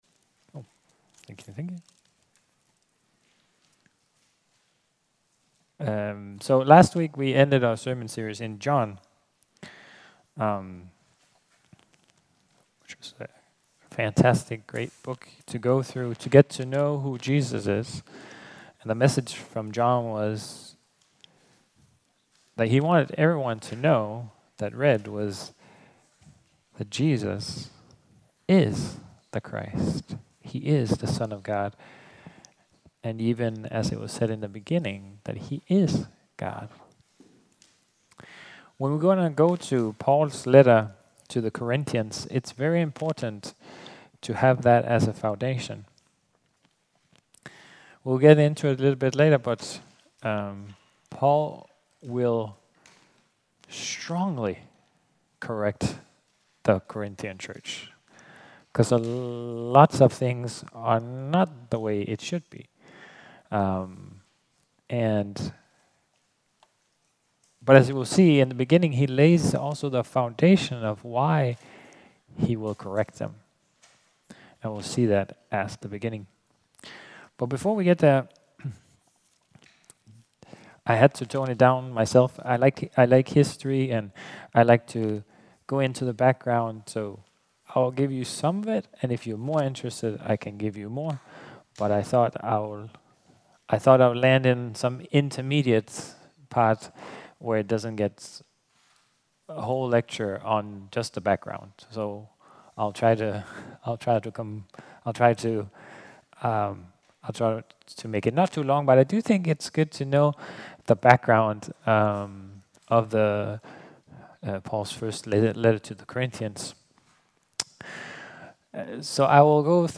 Sermons from Koinonia